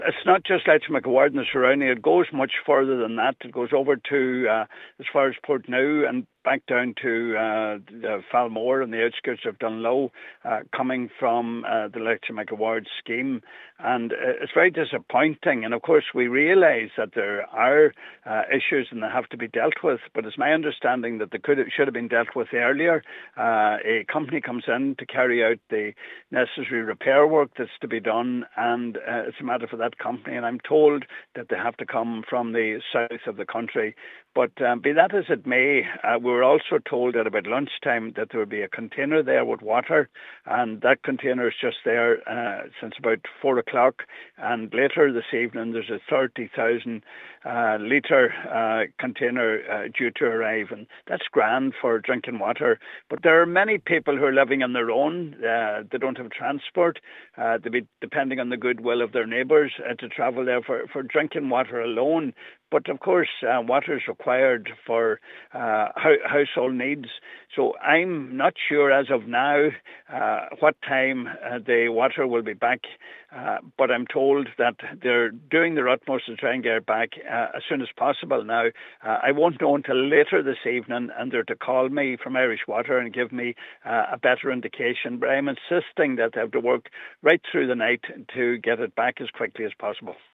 Donegal Deputy Pat the Cope Gallagher says he will be pressing for crews to work through the night to ensure the water supply is restored as soon as possible: